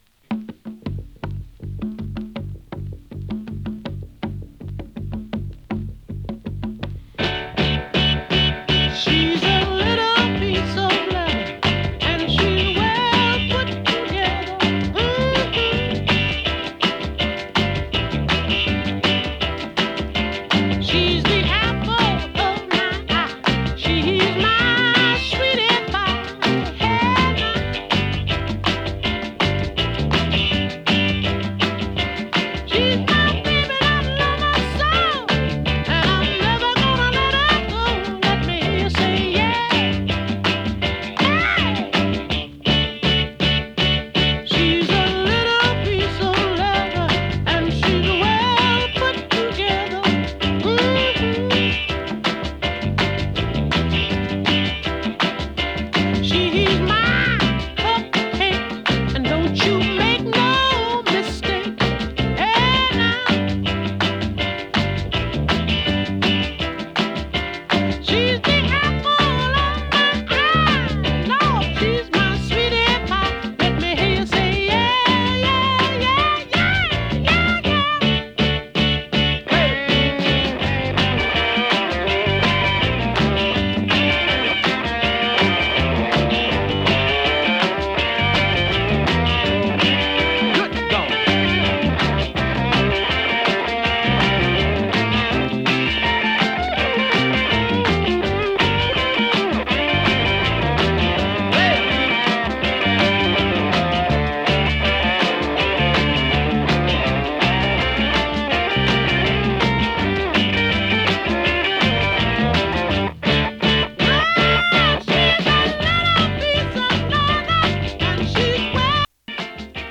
ノーザンソウル人気曲
＊音の薄い部分で稀に軽いチリパチ・ノイズ。